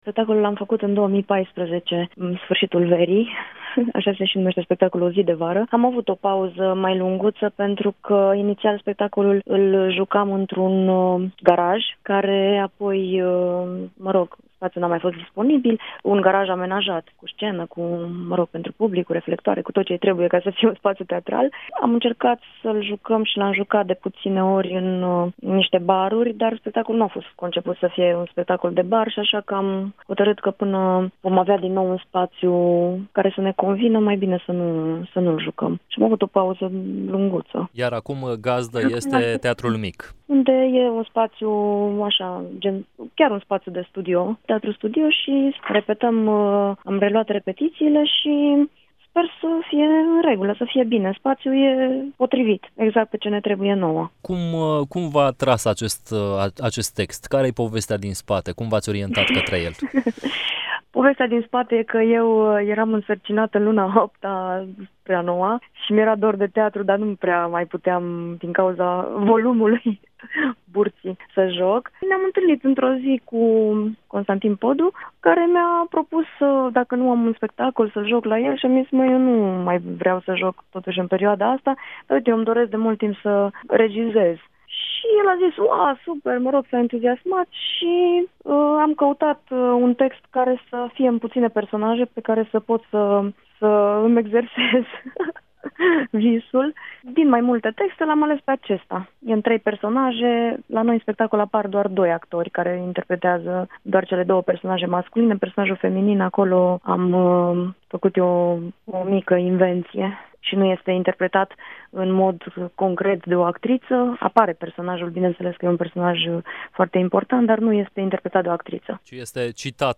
”Nu am vrut să apară și personajul feminin pentru a încărca spectacolul cu și mai mult mister. Pentru că poate să fie …o spectatoare, poate regizorul. Personajul feminim poate avea diferite personalități. Și pot să fiu chiar și eu”, spune actrița Ofelia Popii în interviul acordat Europa FM.